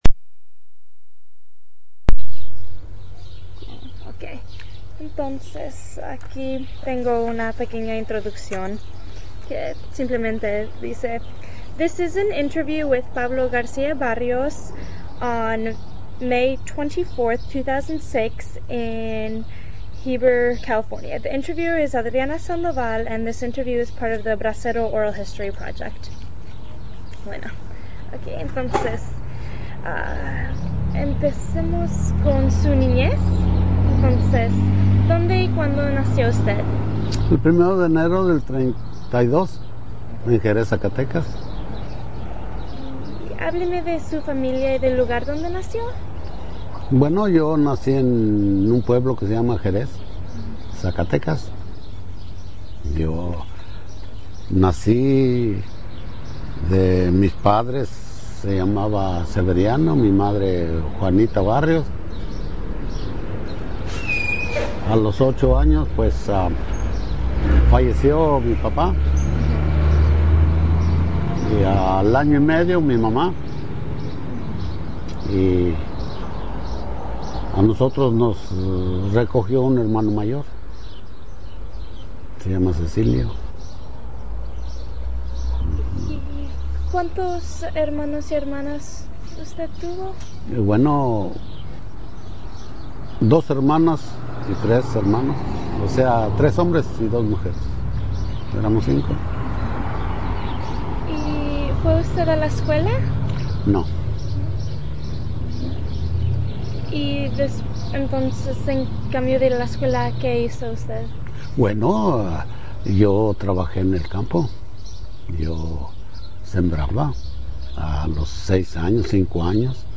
Location Heber, CA